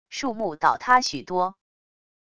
树木倒塌许多wav音频